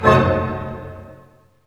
HIT ORCHD0BR.wav